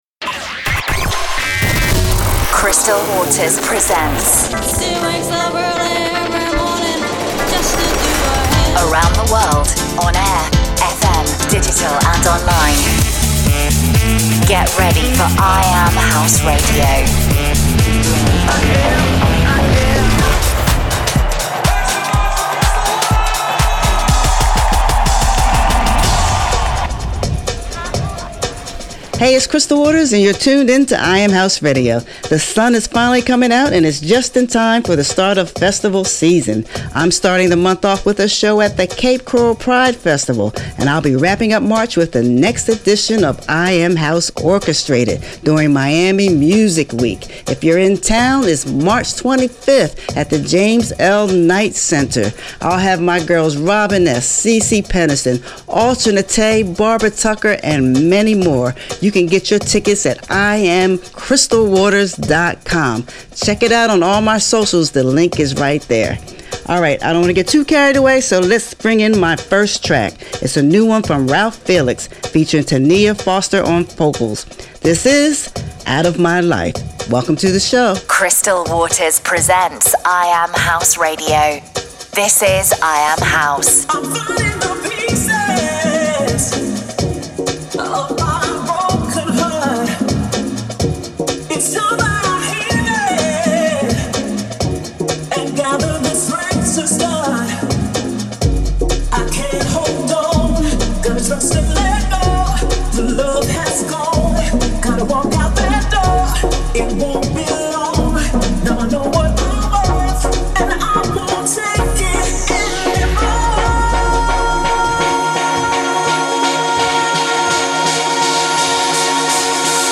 Playing the best new House Music from around the world. 01.